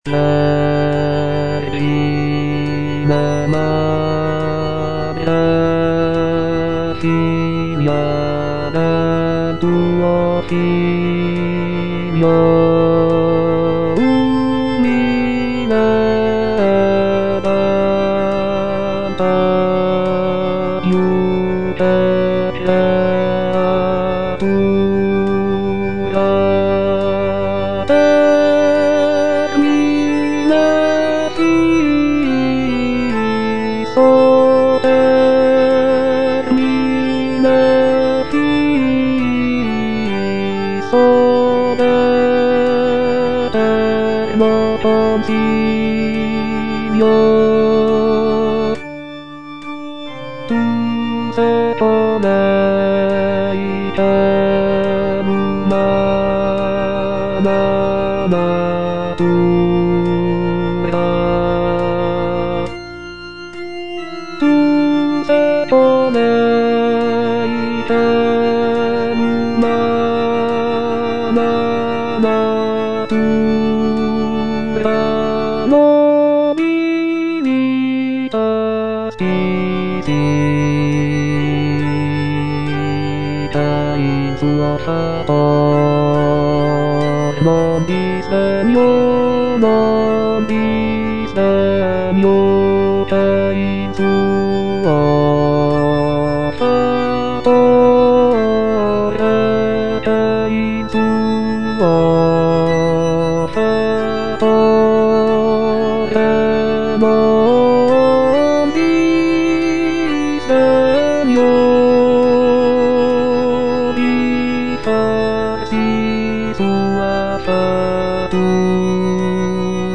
Baritone (Voice with metronome)
serene and ethereal melodies